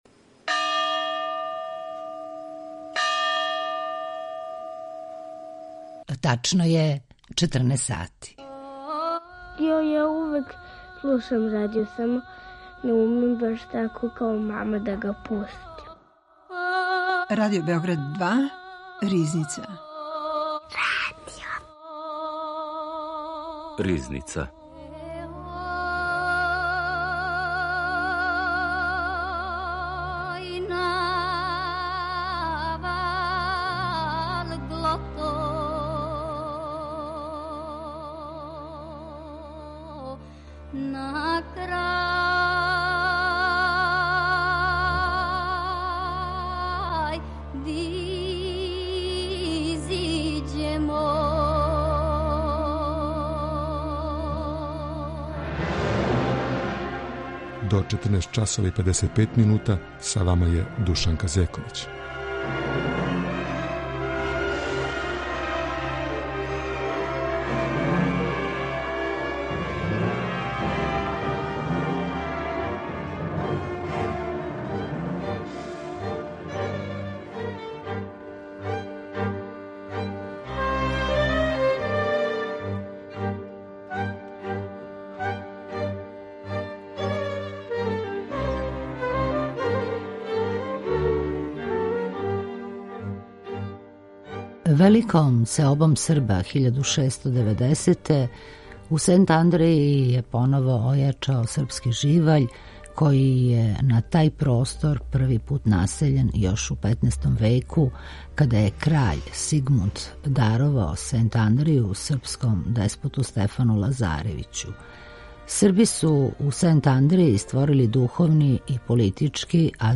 Извор: Радио Београд 2